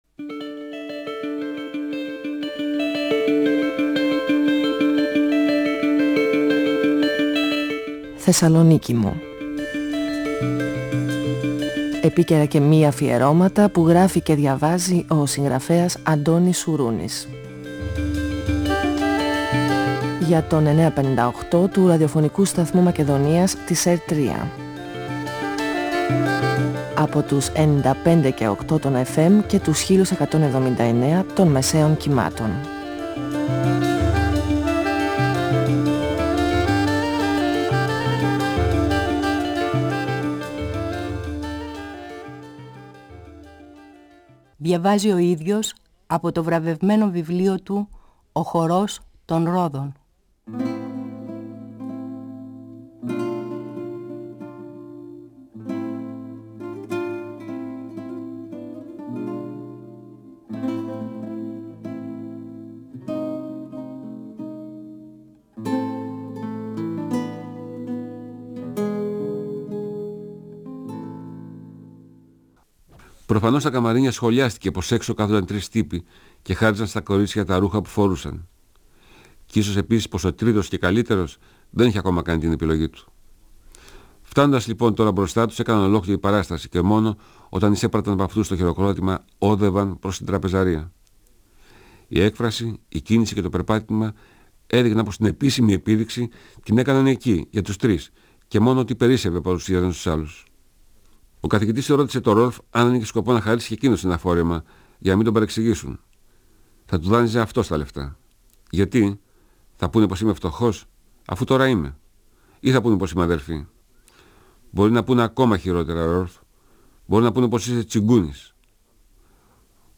Ο συγγραφέας Αντώνης Σουρούνης (1942-2016) διαβάζει το πρώτο κεφάλαιο από το βιβλίο του «Ο χορός των ρόδων», εκδ. Καστανιώτη, 1994. Ο Νούσης, ο Καθηγητής και ο Ρολφ παρακολουθούν τα μανεκέν στην επίδειξη μόδας στο ξενοδοχείο τους. Ο Νούσης ενδιαφέρεται για την Ιρίνα, το μανεκέν, αλλά και εκείνη τον ξεχωρίζει.